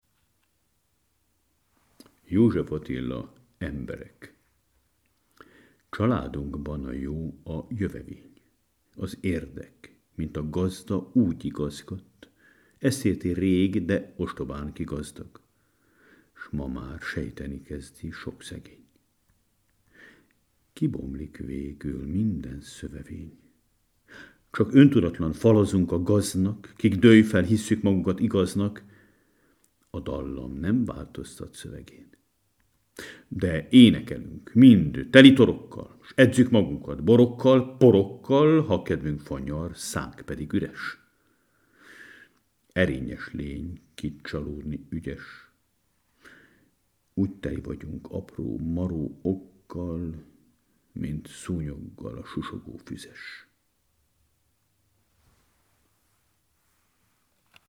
Fogadják szeretettel József Attila: Emberek című költeményét Kocsis Fülöp metropolita tolmácsolásában, melyet i